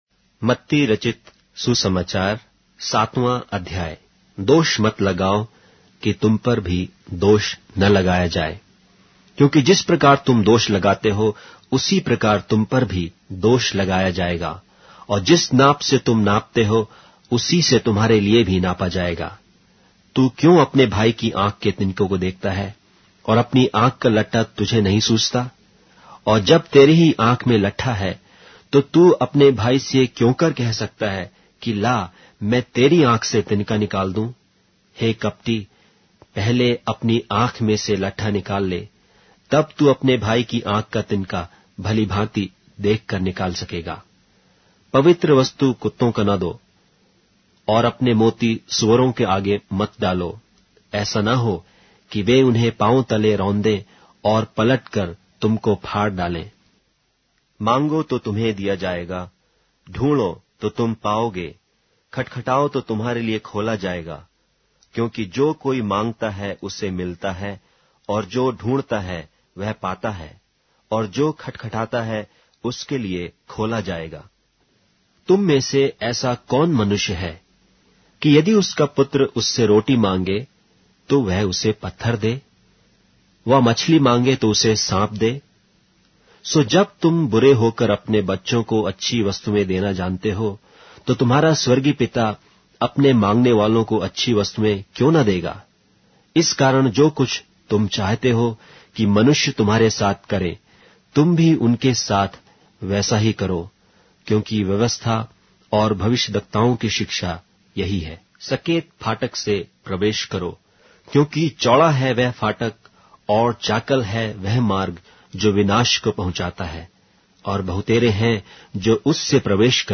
Hindi Audio Bible - Matthew 18 in Esv bible version